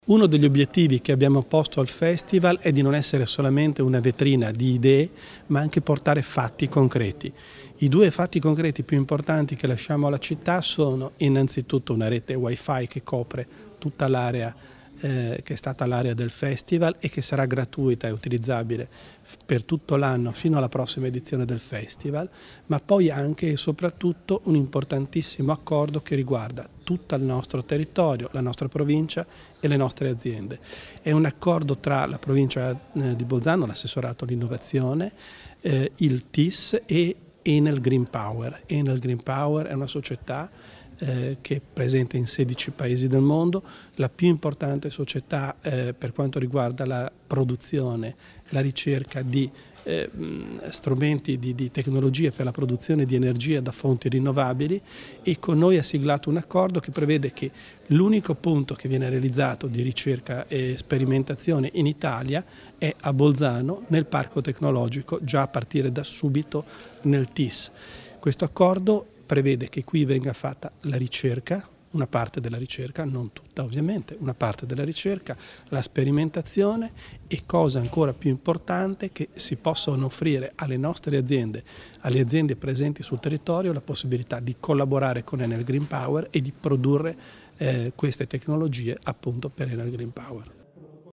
L'Assessore Bizzo spiega l'importanza del protocollo siglato con Enel Green Power